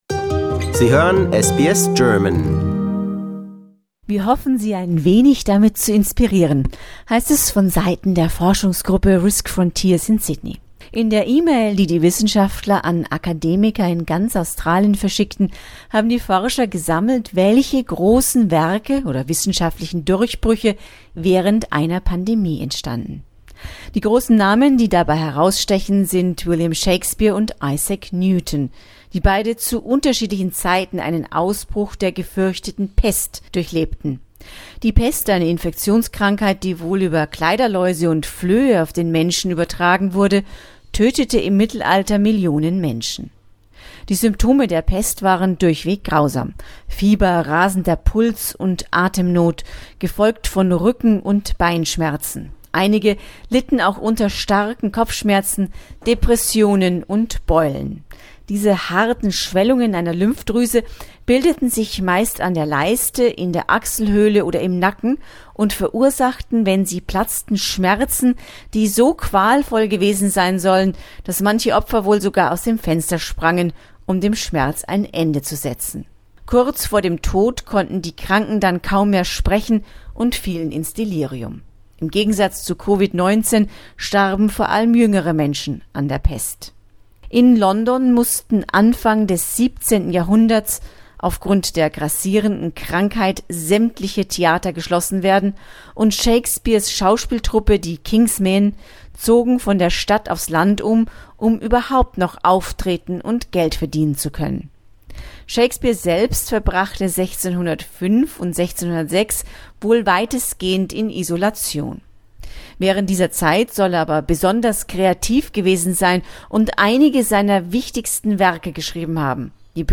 berichtet aus Sydney.